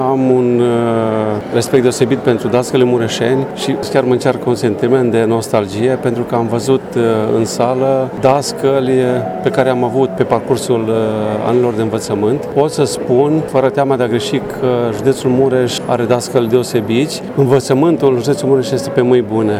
Prima ediție a Galei Educației Mureșene a avut loc astăzi la Palatul Culturii și a fost dedicată dascălilor pensionari, care au primi diplome și medalii.
Vicepreședintele Consiliului Județean Mureș, Alexandru Cîmpeanu: